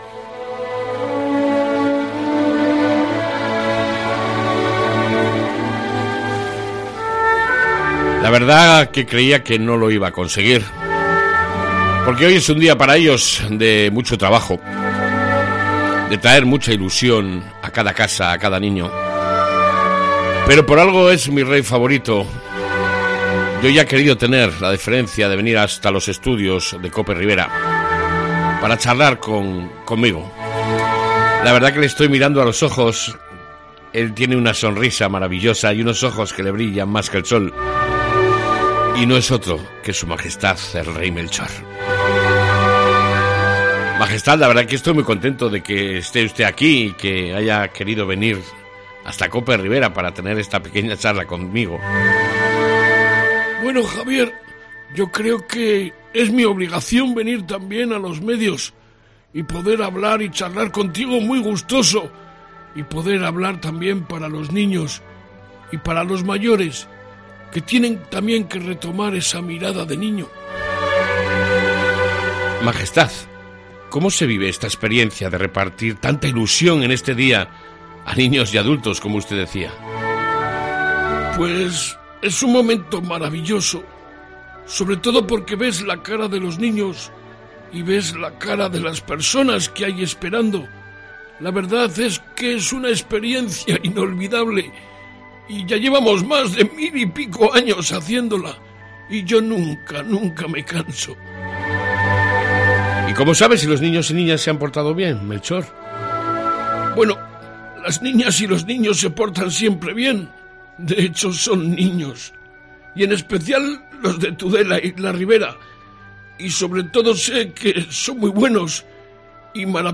AUDIO: Hoy hemos tenido esta Real visita en nuestros estudios de Cope Ribera